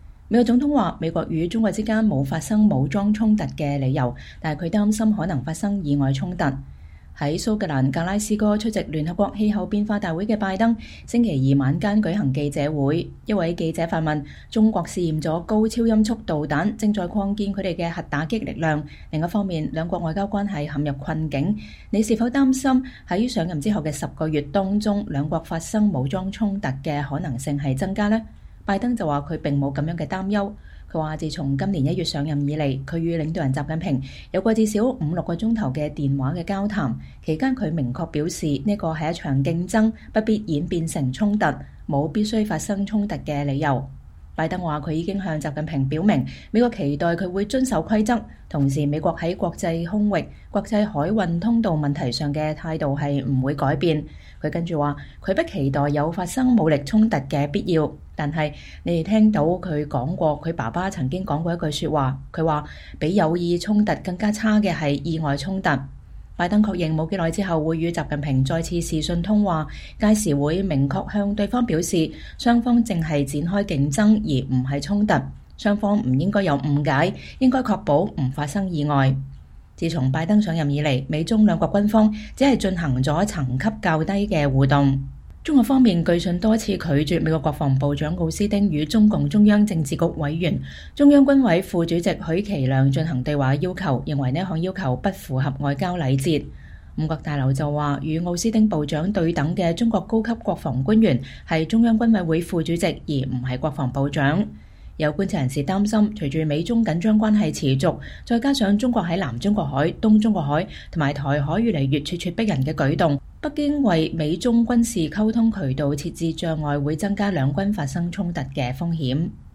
在蘇格蘭格拉斯哥出席聯合國氣候變化大會的拜登星期二（2021年11月2日）晚間舉行記者會。